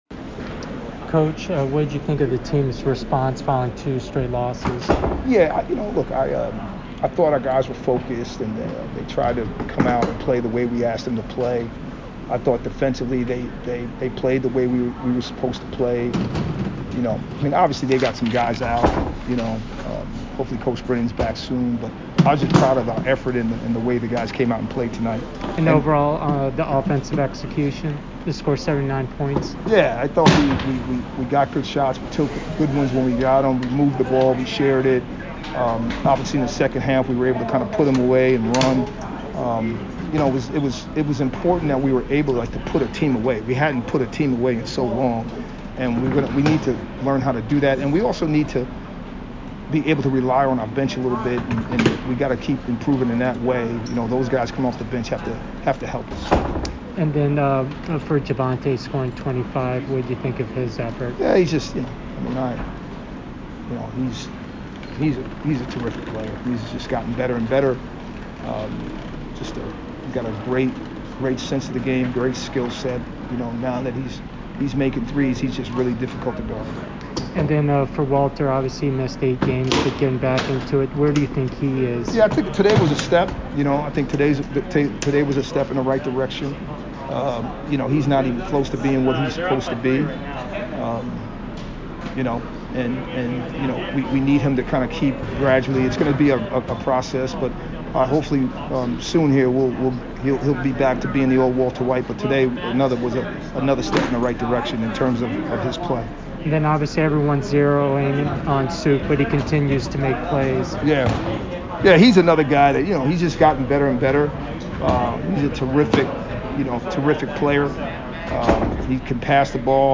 American MBB Postgame Interview